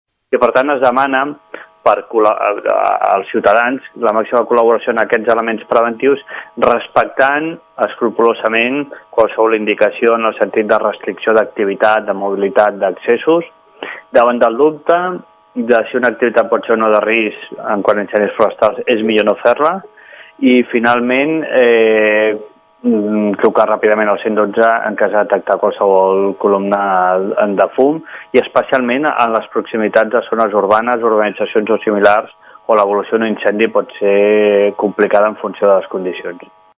Protecció Civil demana prudència i evitar qualsevol activitat que pugui provocar un foc. el sotsdirector de Protecció Civil, Sergio Delgado, demana no abaixar la guàrdia perquè tornem a estar en prealerta per risc alt d’incendi pràcticament a tota Catalunya.